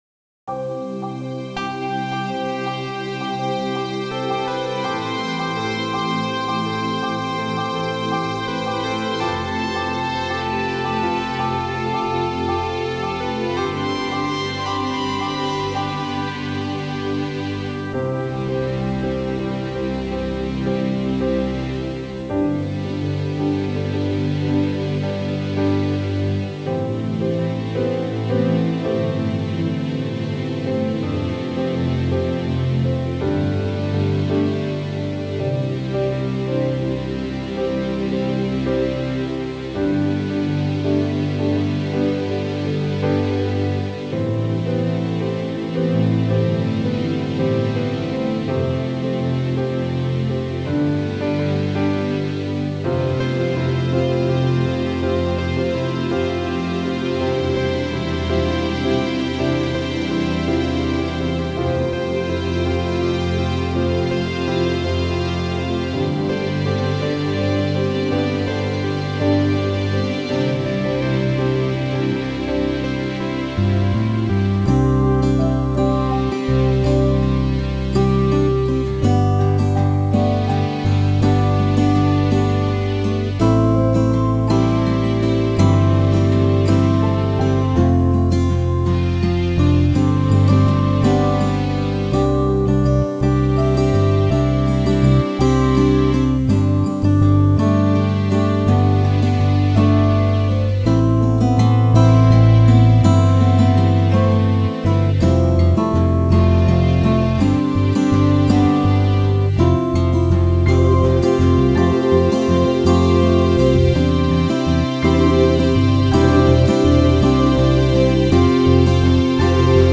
MUSIC AUDIO ONLY
Safe_TRAX_Traditional.wav